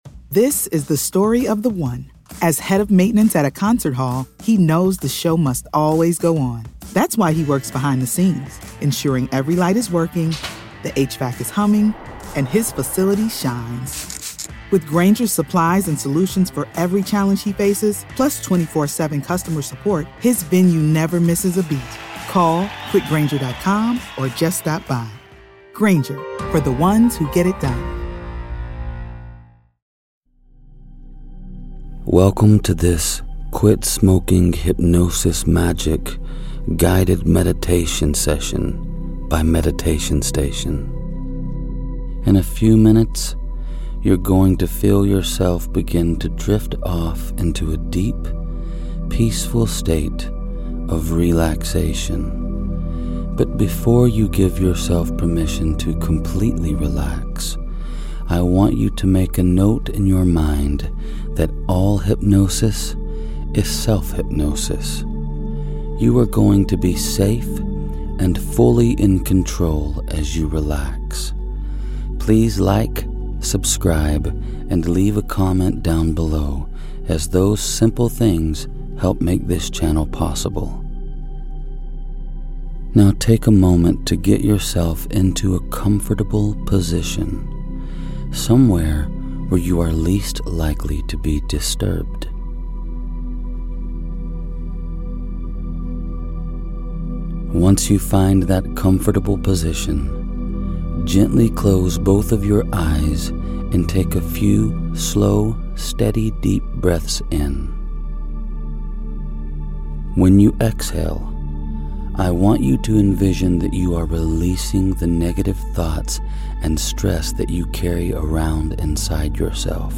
Stop smoking right now. Sleep hypnosis session for cleansing air and freedom.